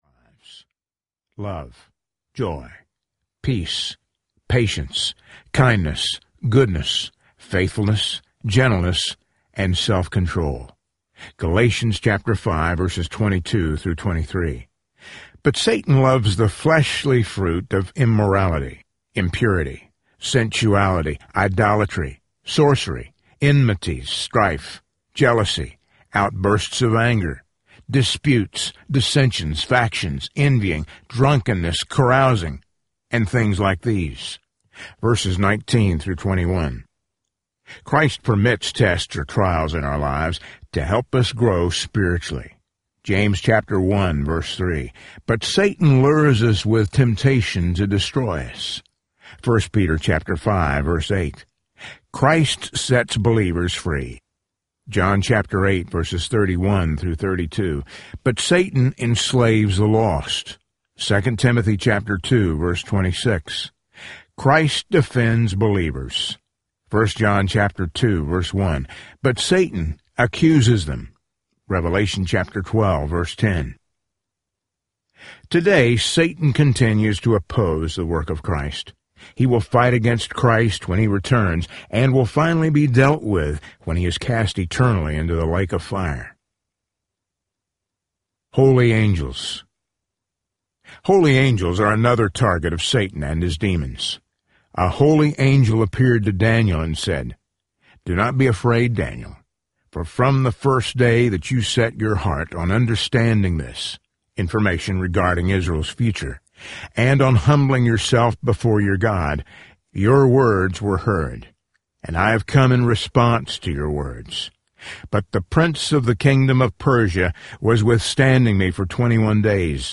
Standing Strong Audiobook
Narrator
6.8 Hrs. – Unabridged